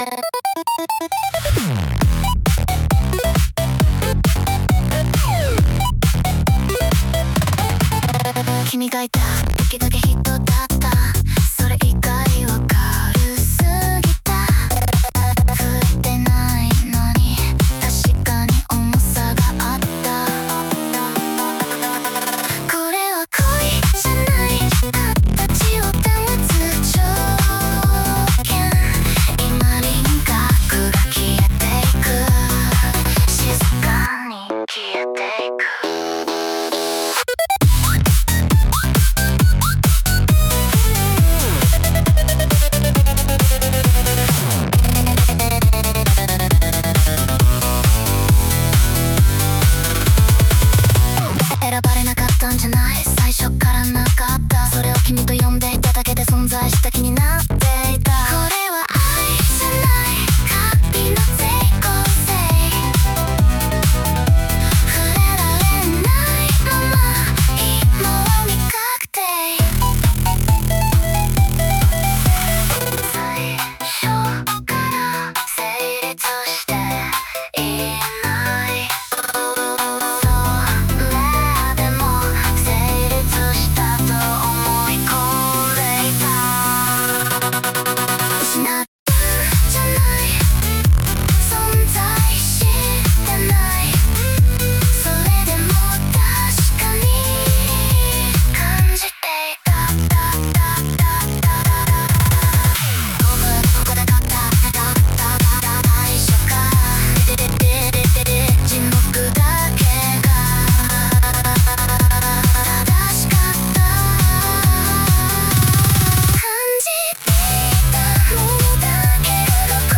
女性ボーカル
イメージ：8-Bit,女性ボーカル,レトロＫポップ,ダーククラブポップかっこいい